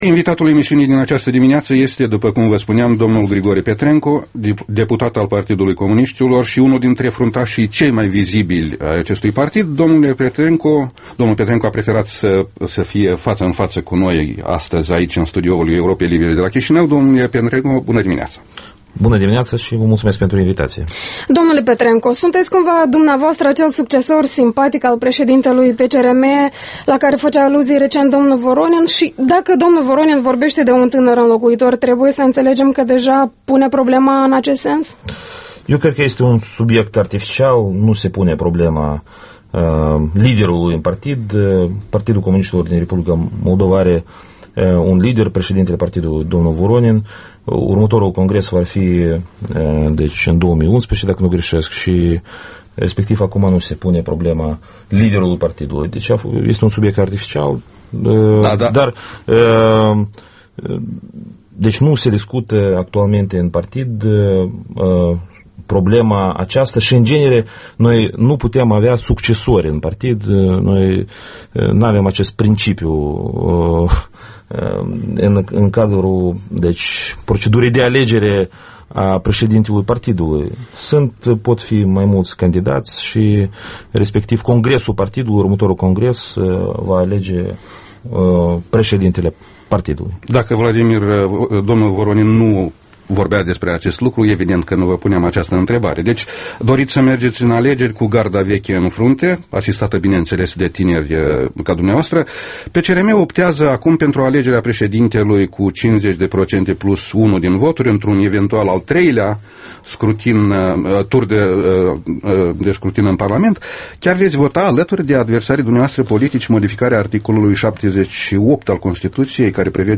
Interviul matinal EL: cu Grigore Petrenco